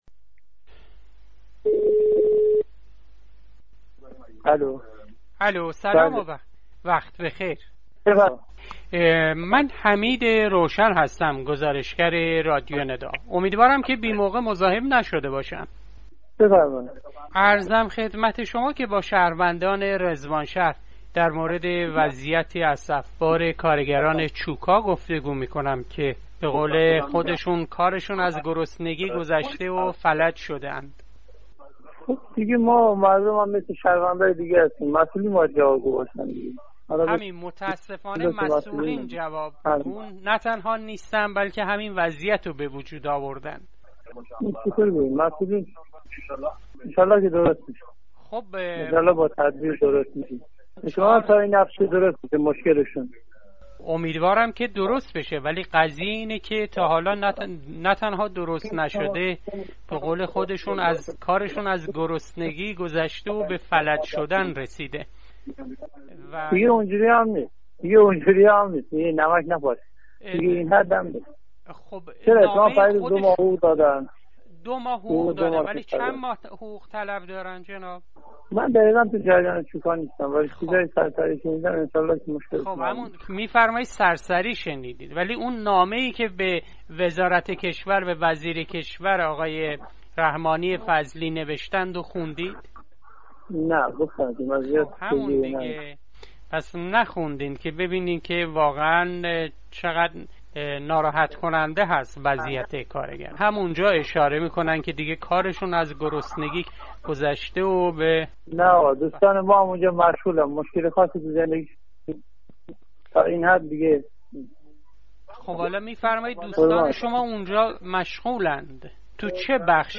گفت و گوي راديو ندا با تني چند از كارگران شركت چوب و كاغذ ايران ( چوكا ) و شهروندان رضوانشهر پيرامون وضعيت كارگران بزرگترين كارخانه توليد كننده چوب و كاغد در خاورميانه كه حتي نان خالي را هم قسطي مي خرند!